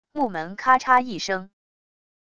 木门咔嚓一声wav音频